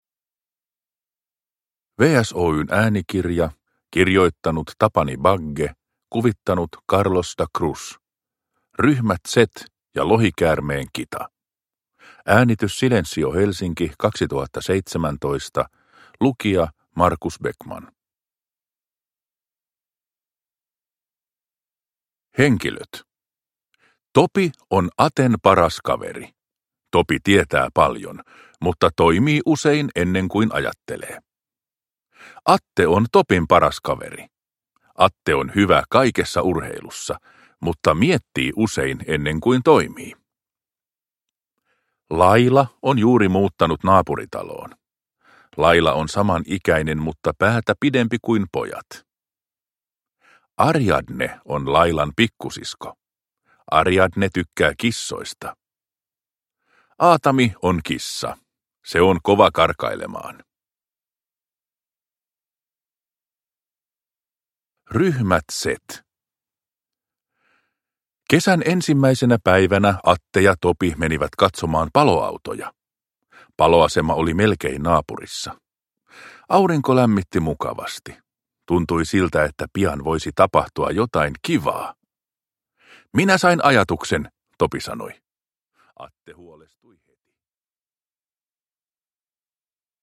Ryhmä Z ja lohikäärmeen kita – Ljudbok